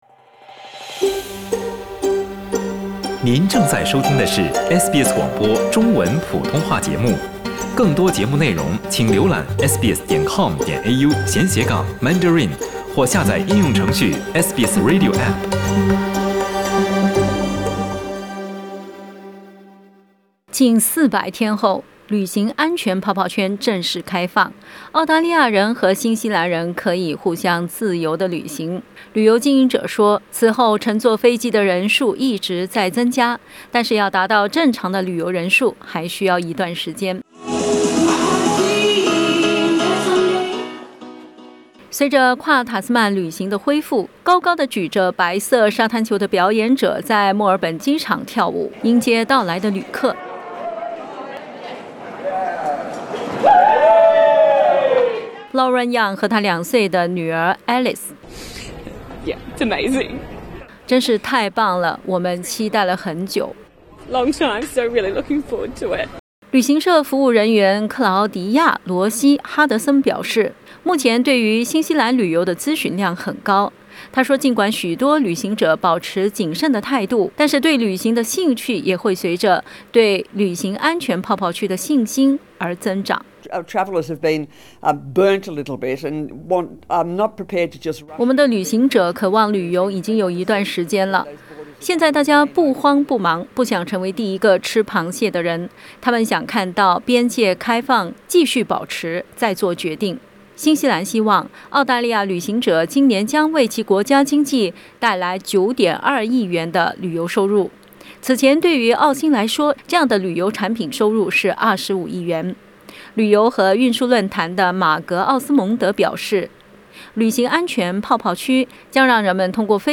（請聽報道） 澳大利亞人必鬚與他人保持至少1.5米的社交距離，請查看您所在州或領地的最新社交限制措施。